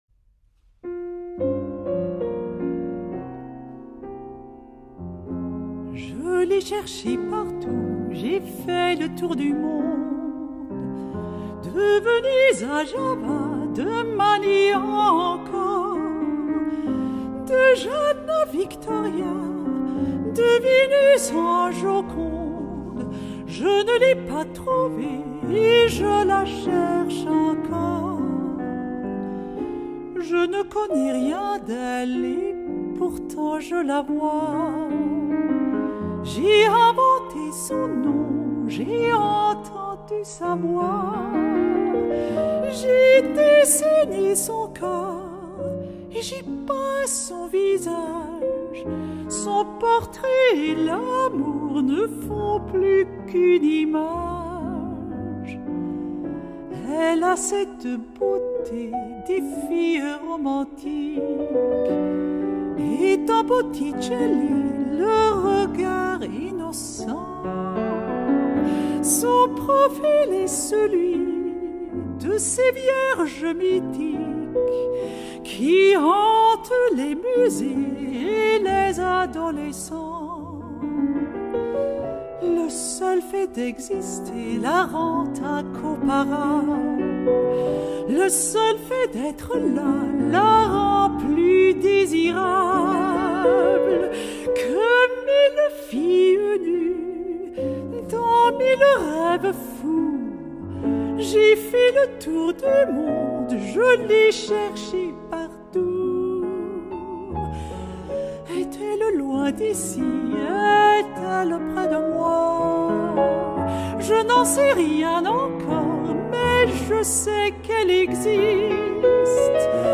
pianist